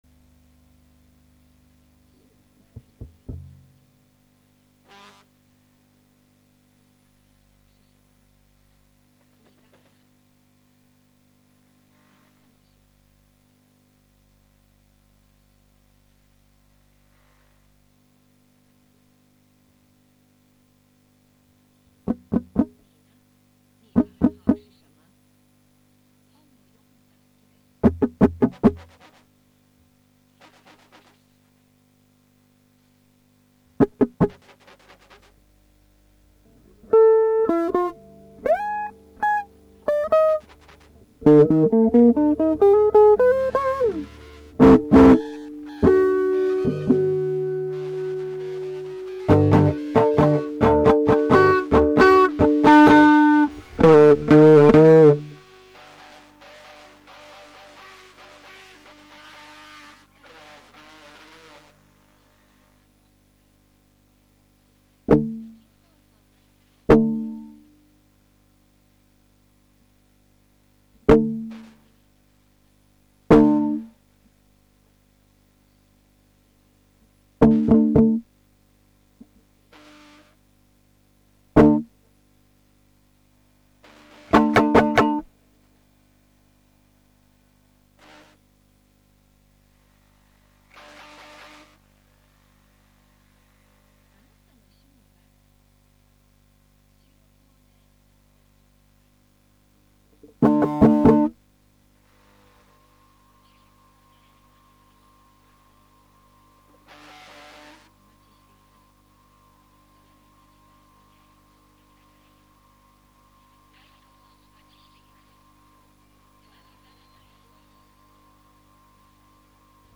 張整の途中またもゃラジオを受信、これも
radioII.mp3